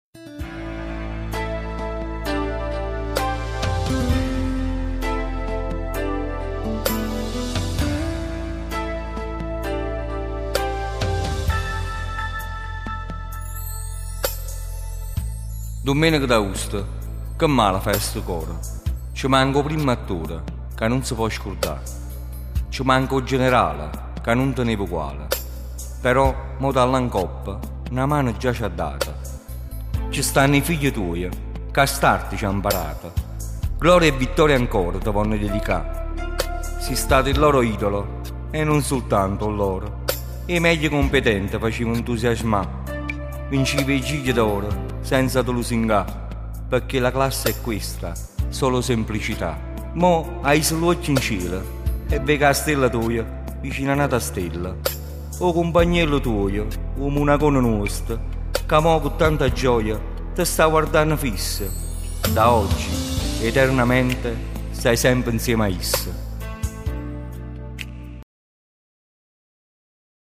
Poesia